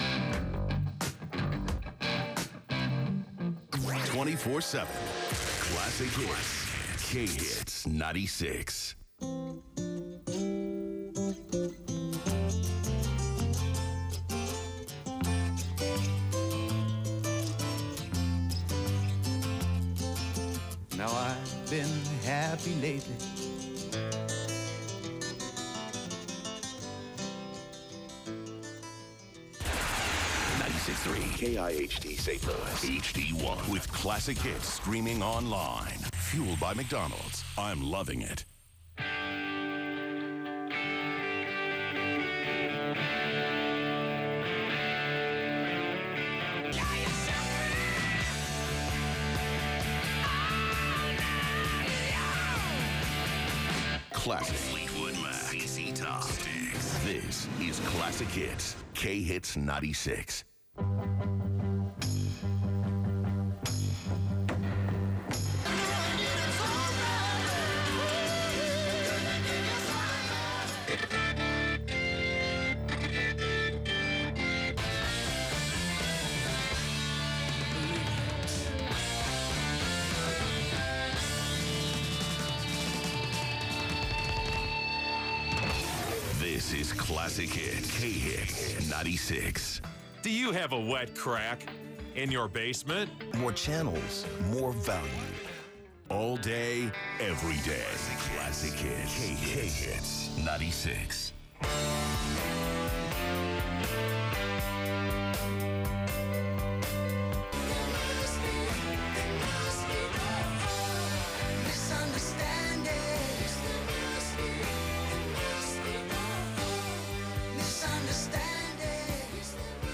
KIHT Automation Aircheck · St. Louis Media History Archive